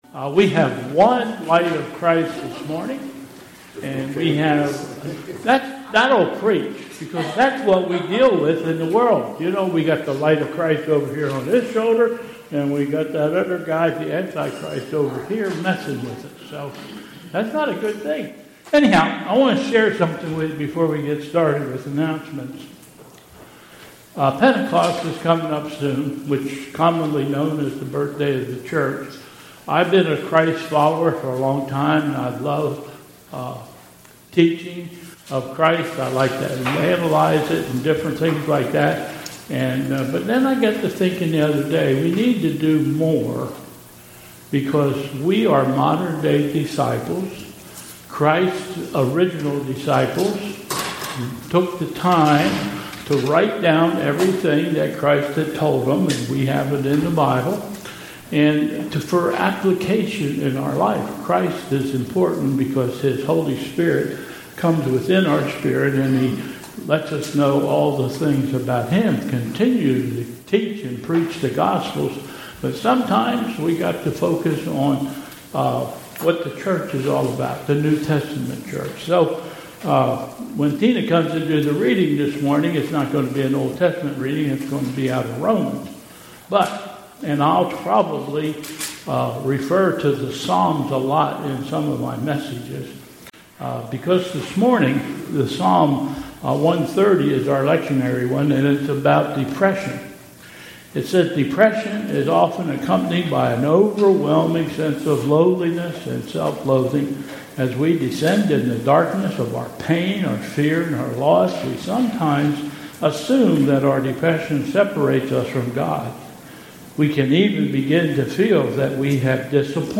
Bethel Church Service
Prelude: "Sing Alleluia to the Lord"